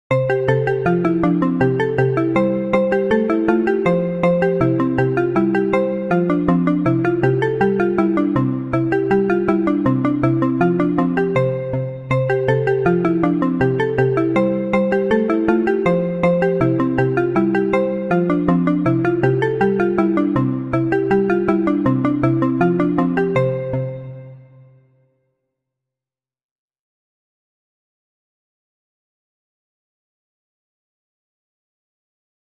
BPM160
勝利 明るい 楽しい 軽快